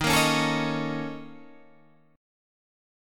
BbmM7bb5/Eb Chord
Listen to BbmM7bb5/Eb strummed